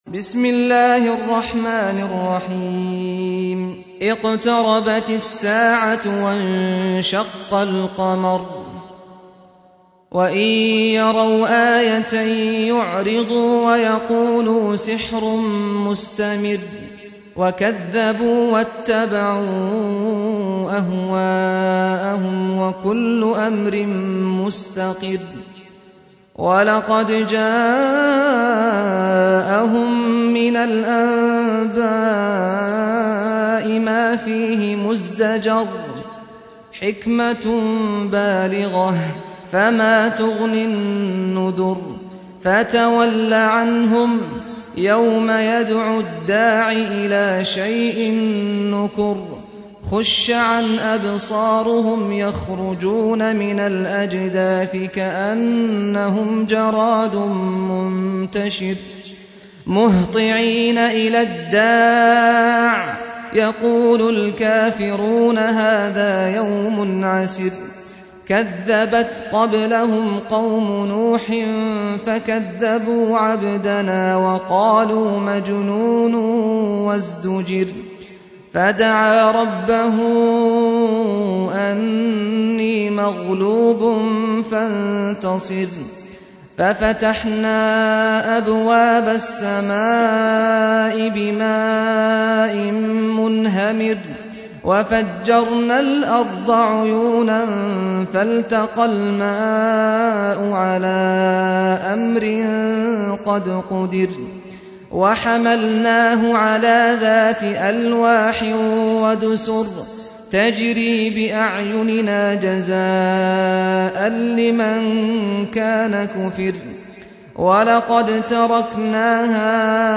سُورَةُ القَمَرِ بصوت الشيخ سعد الغامدي